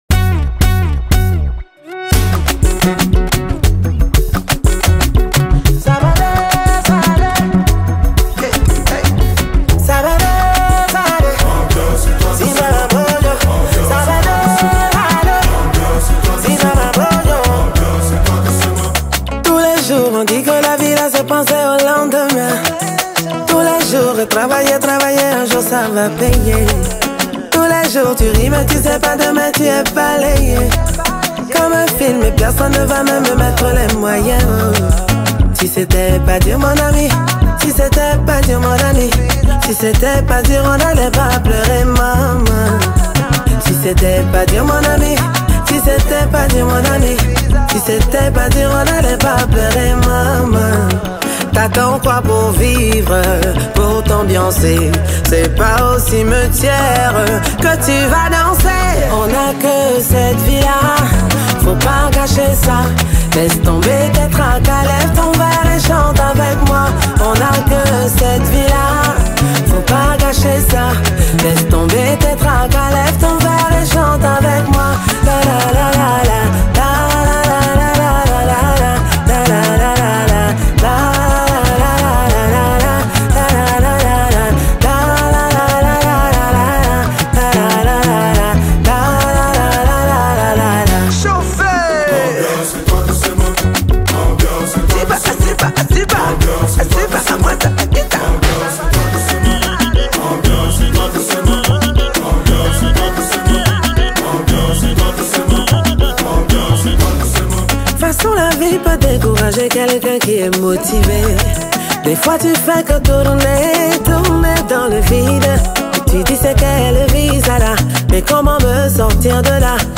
| Afro pop